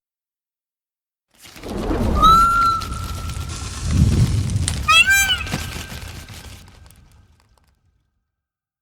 Animals